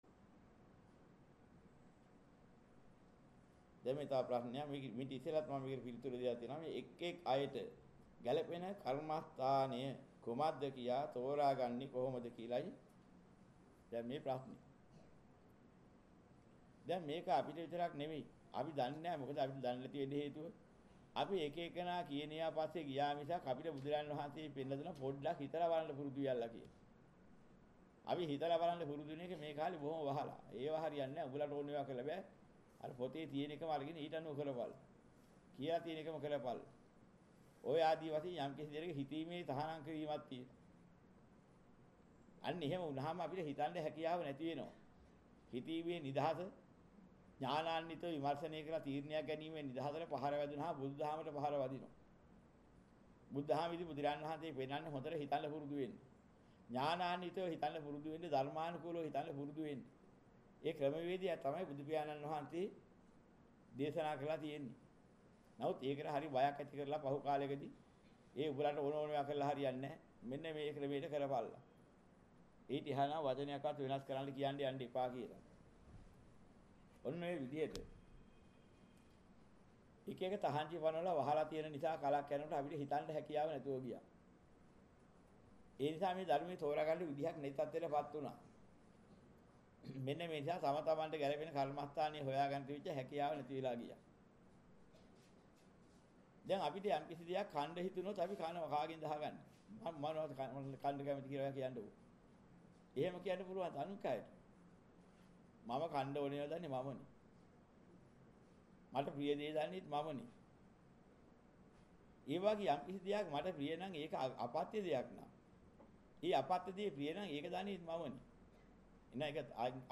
මෙම දේශනය පසුව සවන් දීමට අවැසි නම් මෙතැනින් බාගත කරන්න